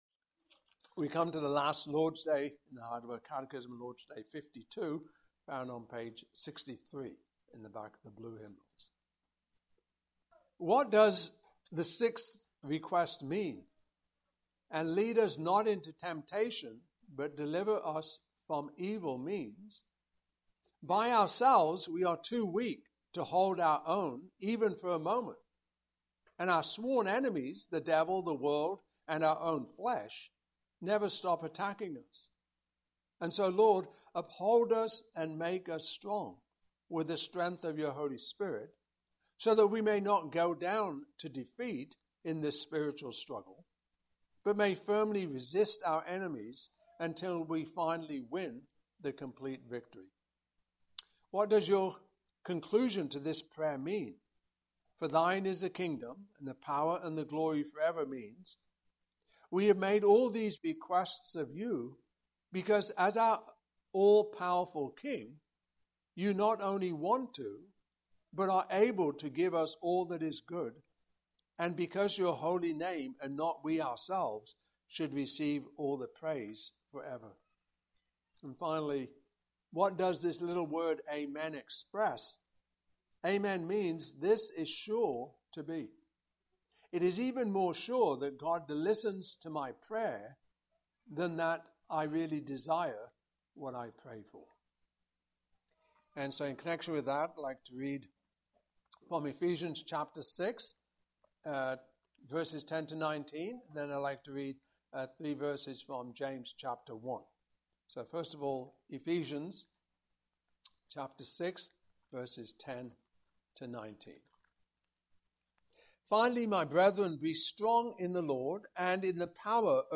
Passage: Ephesians 6:10-19, James 1:12-16 Service Type: Evening Service